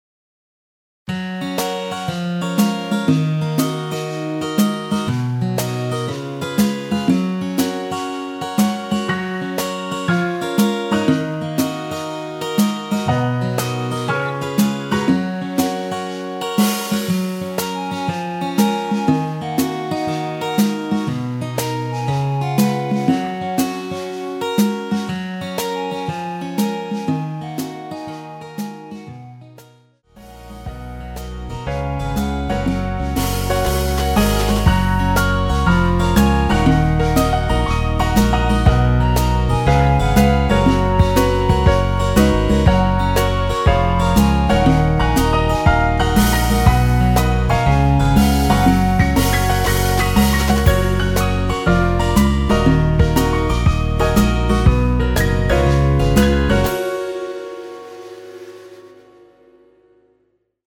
엔딩이 페이드 아웃에 너무 길어서 4마디로 엔딩을 만들었습니다.(미리듣기 참조)
원키에서(+6)올린 멜로디 포함된 MR 입니다.
F#
앞부분30초, 뒷부분30초씩 편집해서 올려 드리고 있습니다.